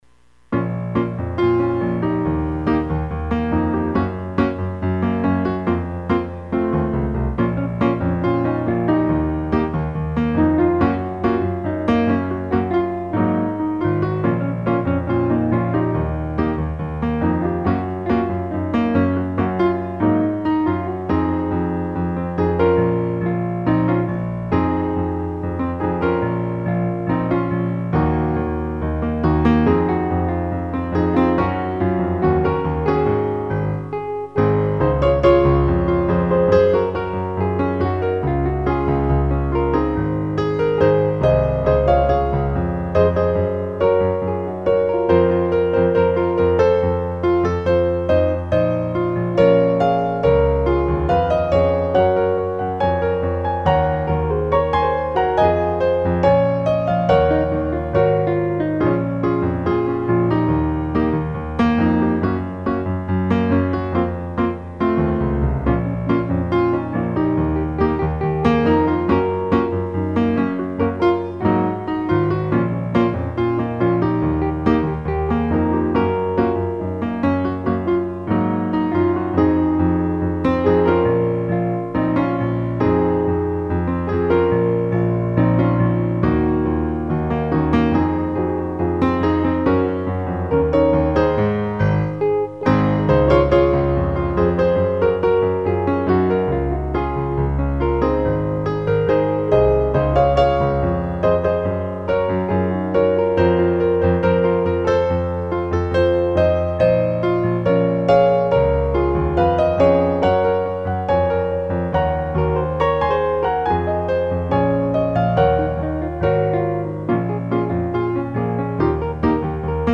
When-We-Are-Singing-a-Song---2-140-bpm-TRACK.mp3